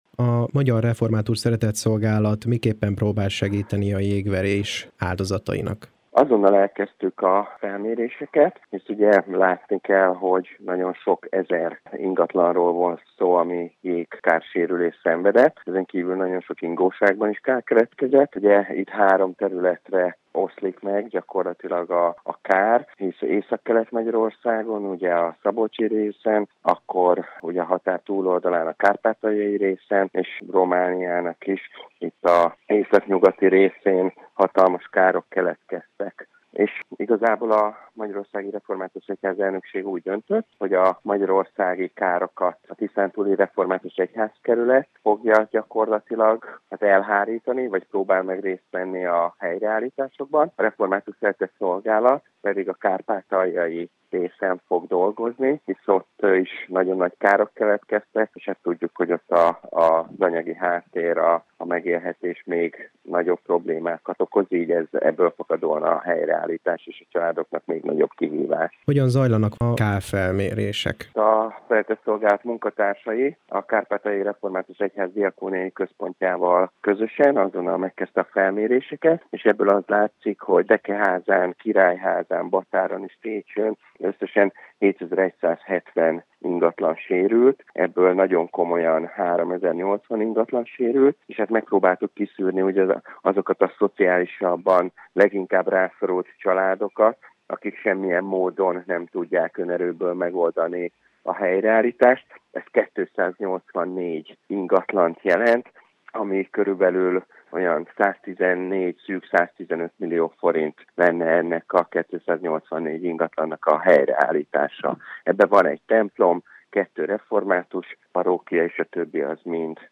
mrsz-jegveres-adomanygy-interju.mp3